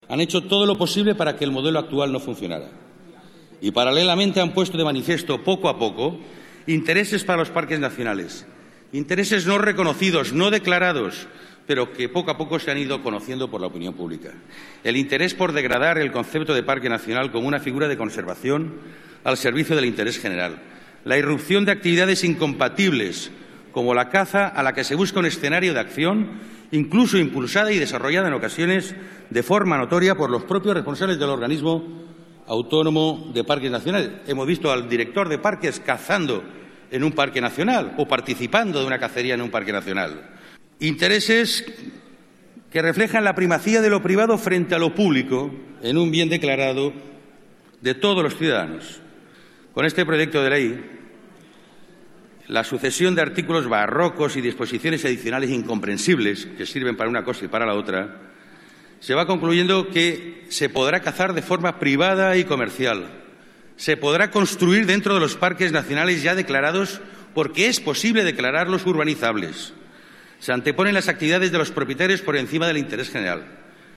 Debate en el Congreso de los Diputados sobre el proyecto de ley de Parques Nacionales 29/05/2014